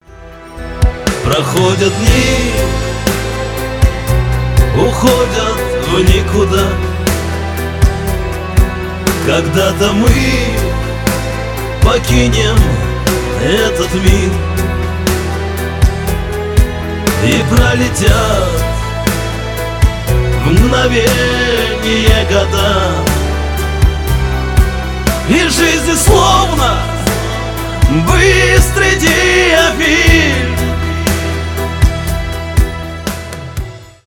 грустные , шансон